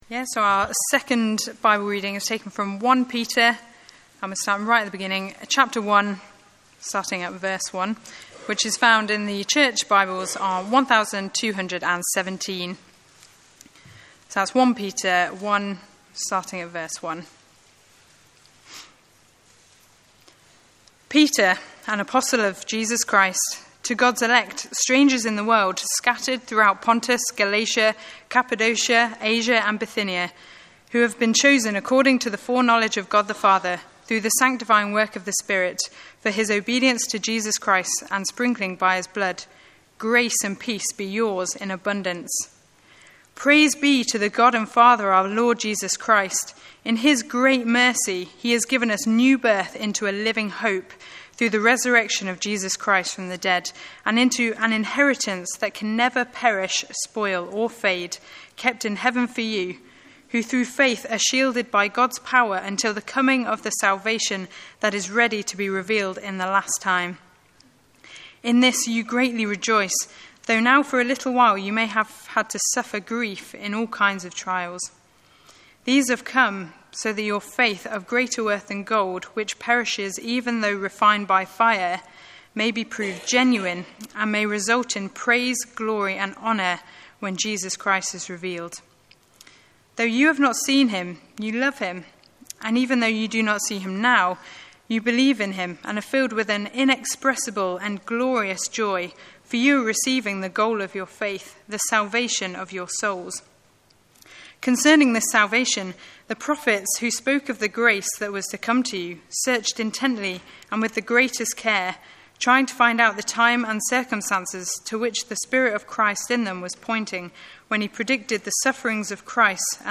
Sermons Archive - Page 91 of 188 - All Saints Preston